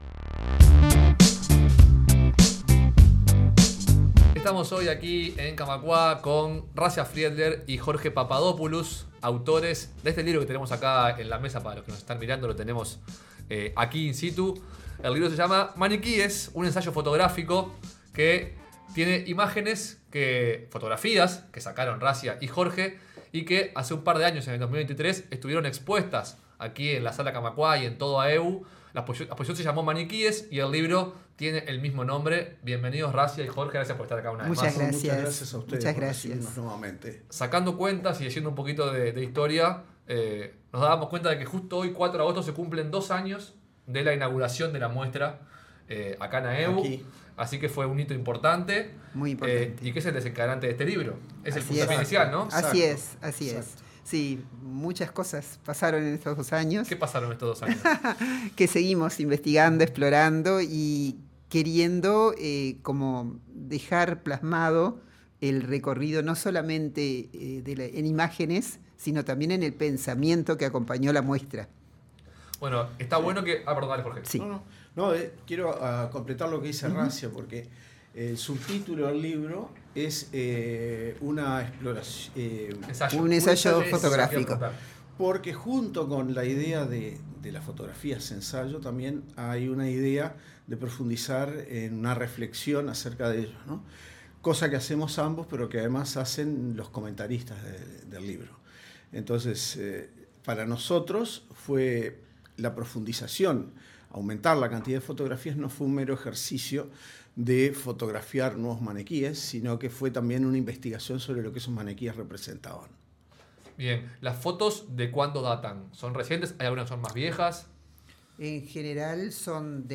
En entrevista con Radio Camacuá , los coautores de la obra describieron al libro como un «ensayo fotográfico», dado que además de imágenes presenta un pensamiento filosófico sobre la figura del maniquí.